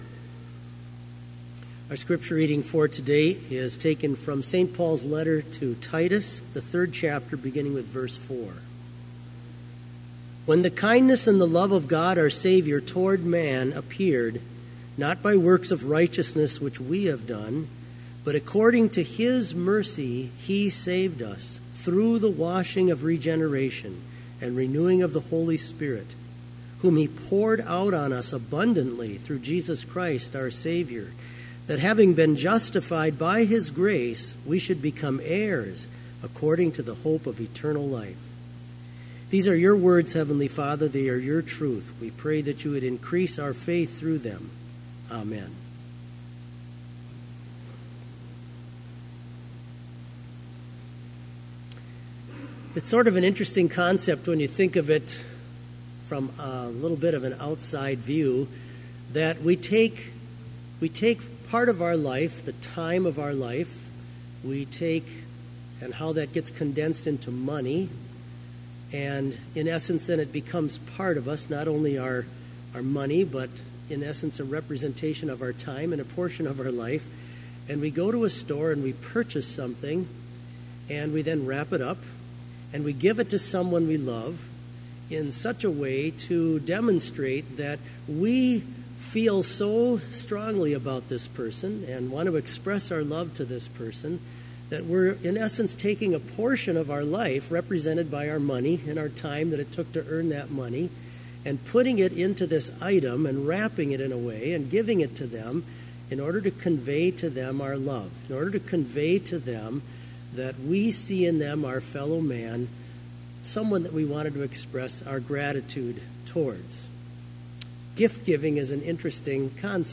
Complete service audio for Chapel - December 14, 2017
Prelude Hymn 145, What Child Is This Reading: Titus 3:4-7 Devotion Prayer Hymn 114, Angels from the Realms of Glory Blessing Postlude